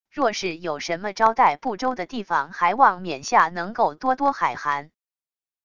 若是有什么招待不周的地方还望冕下能够多多海涵wav音频生成系统WAV Audio Player